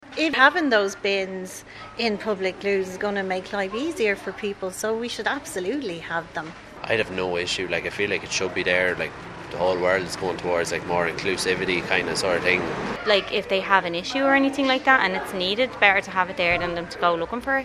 These people agree bins should be available.